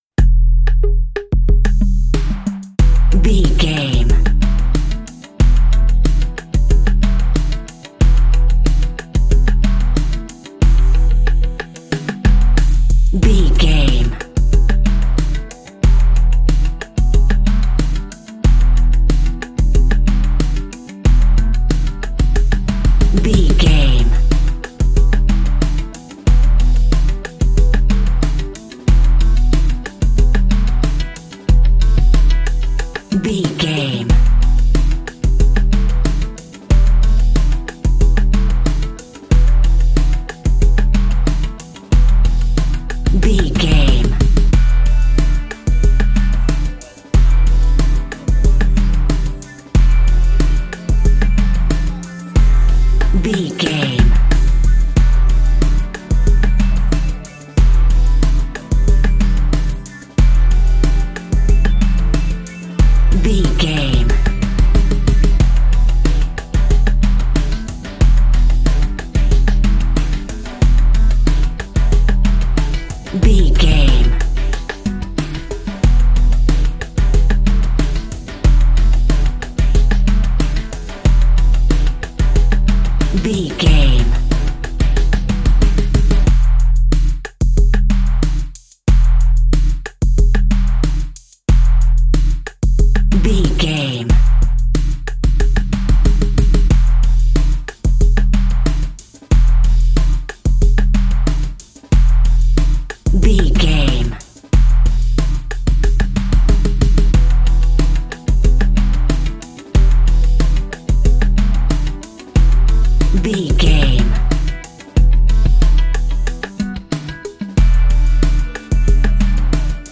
Aeolian/Minor
synthesiser
drum machine
hip hop
Funk
neo soul
acid jazz
confident
energetic
bouncy
funky